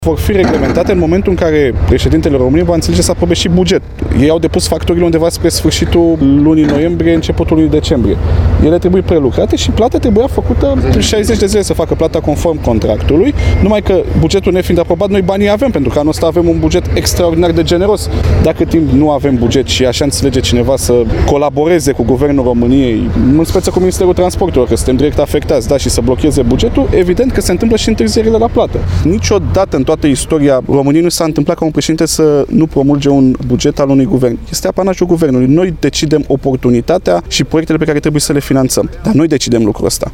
Ministrul Transporturilor RĂZVAN CUC a declarat, la Suceava, că blocarea bugetului va duce la întârzierea la plată a constructorilor, dar că, în prezent, nu sunt datorii faţă de aceştia.